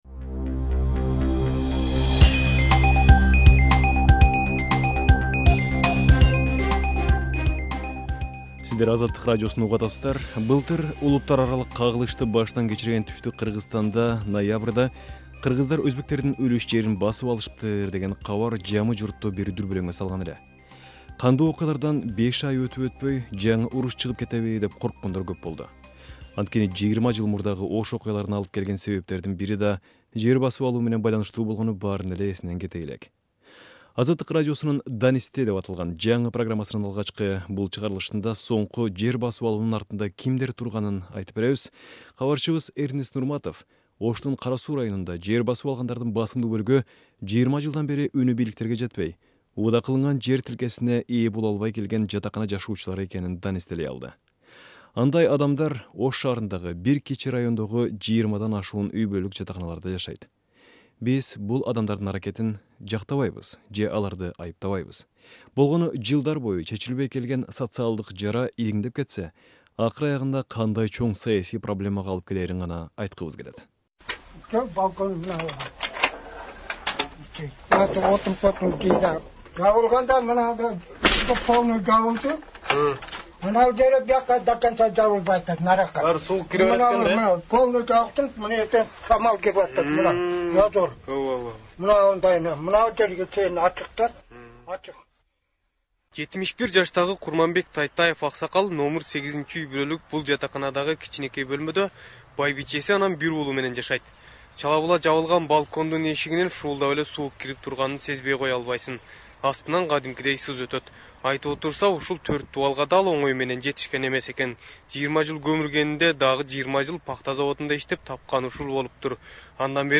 "Азаттык" документалдуу радио баяндардын "Данисте" түрмөгүн баштады. Алгачкы чыгарылышта Ошто жер басып алгандар жөнүндө кеңири баян этилет.